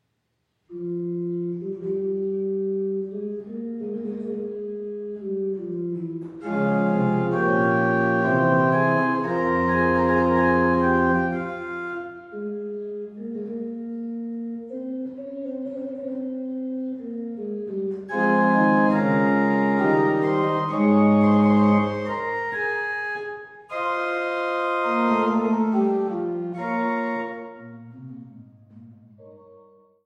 an der historischen Orgel zu Niederndodeleben
Orgel